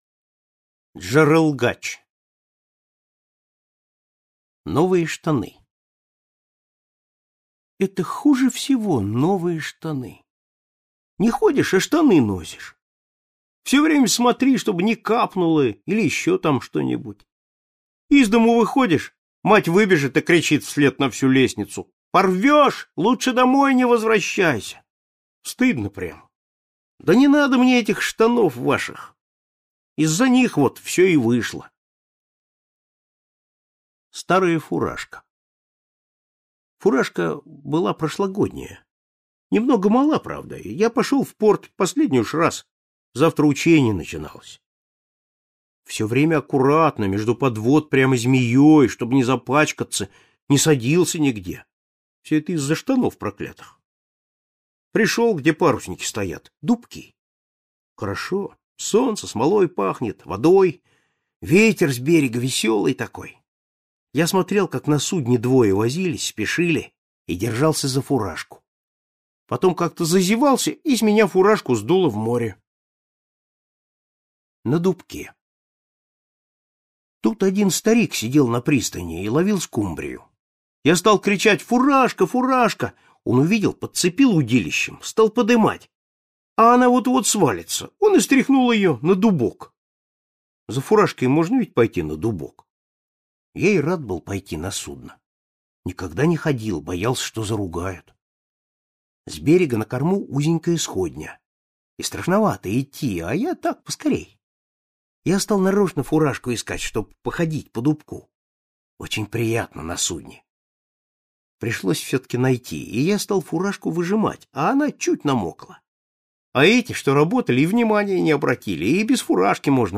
Джарылгач - аудио рассказ Житкова - слушать онлайн